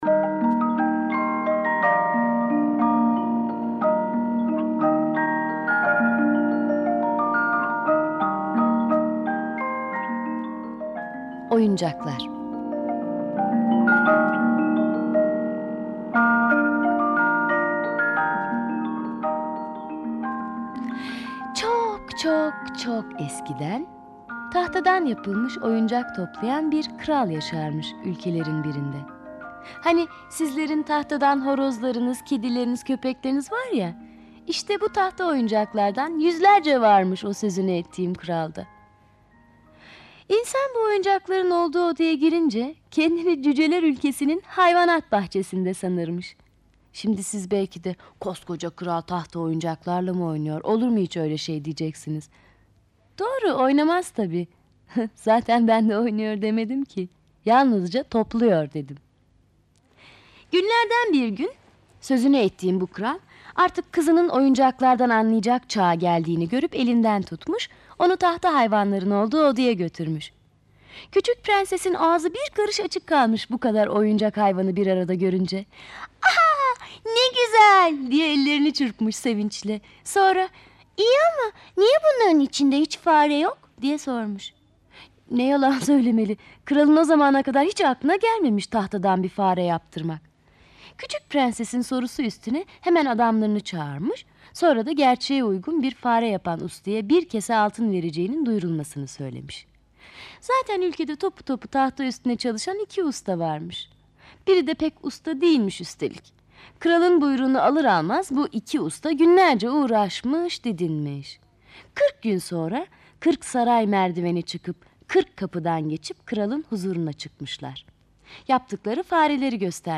Oyuncaklar sesli masalı, mp3 dinle indir
Oyuncaklar sesli masalı, mp3 olarak sitemize eklenmiştir.
Sesli Çocuk Masalları